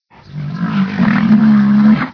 c_rhino_bat1.wav